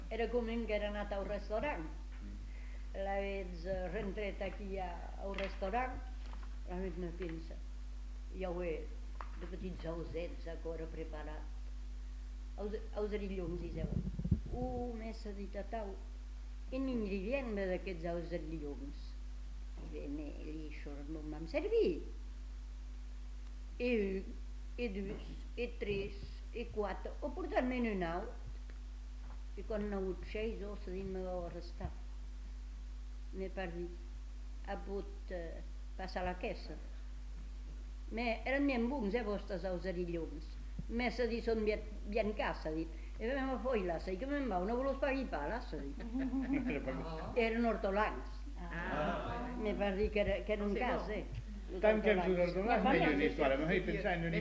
Lieu : Uzeste
Genre : conte-légende-récit
Type de voix : voix de femme
Production du son : parlé
Classification : récit anecdotique